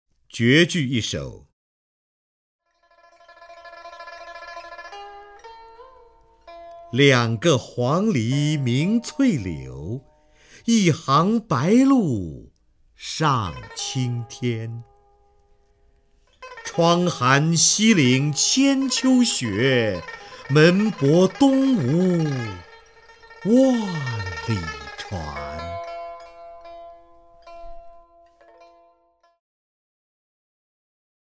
首页 视听 名家朗诵欣赏 瞿弦和
瞿弦和朗诵：《绝句·两个黄鹂鸣翠柳》(（唐）杜甫)